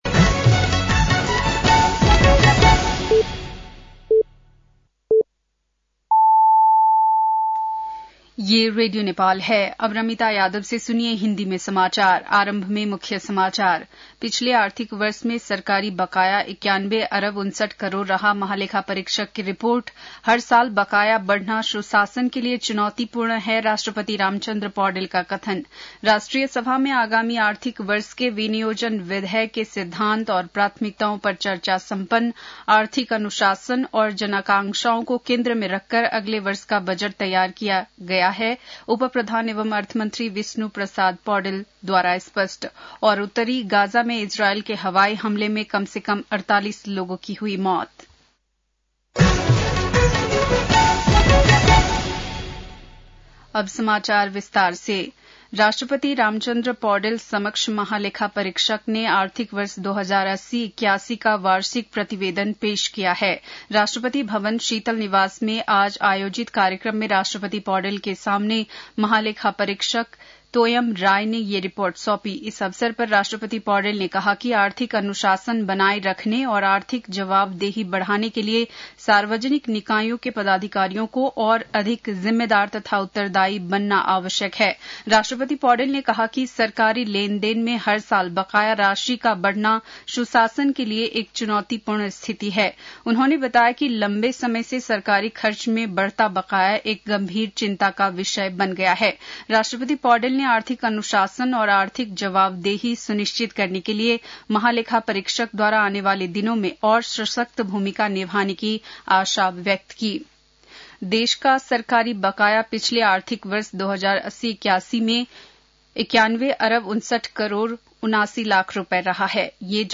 बेलुकी १० बजेको हिन्दी समाचार : ३१ वैशाख , २०८२